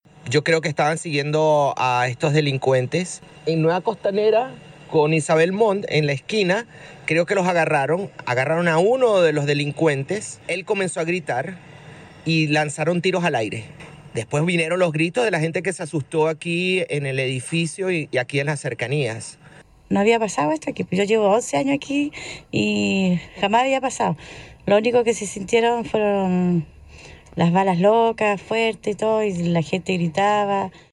Así lo relató un vecino del sector.
cu-vecinos-encerrona-vitacura.mp3